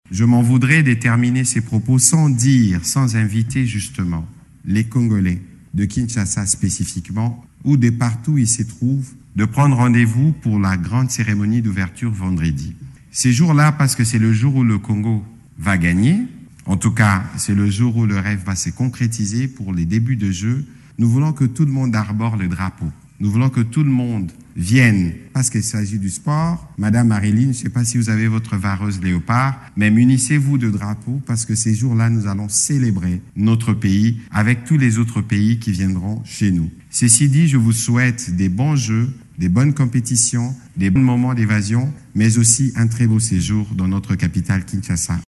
Tel est l’appel lancé du porte-parole du gouvernement congolais, Patrick Muyaya, lancé mercredi 26 juillet au cours de la conférence de presse de lancement de IXes Jeux de la Francophonie.